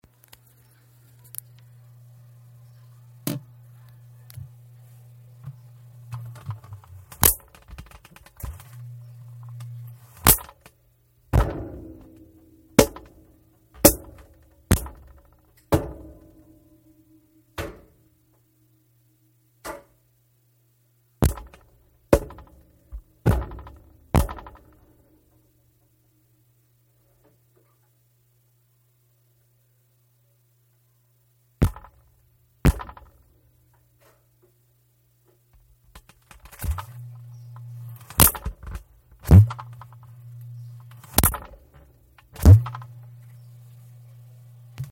钢轮长、短打" 木棍打在钢轮上，发出闷响
描述：木棍击中钢轮，用手闷响。短暂的支持。
Tag: 金属 命中 冲击